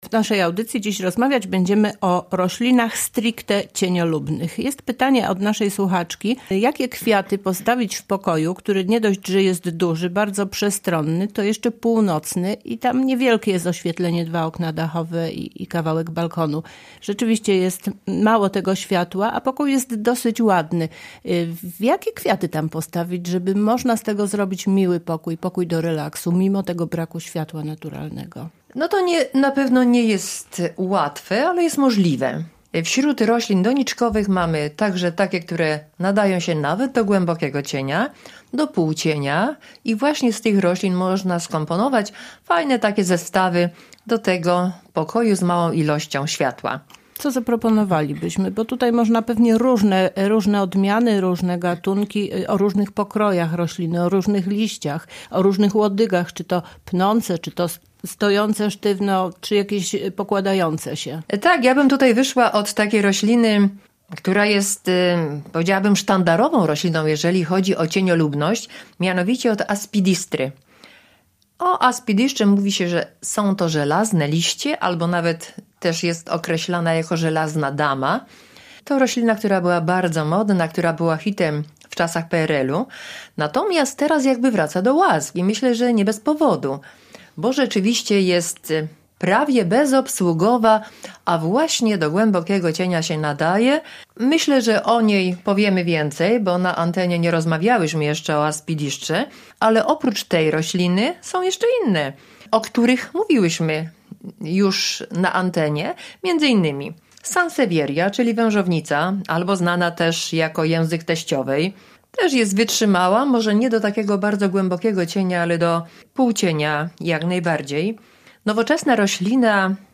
rozmowie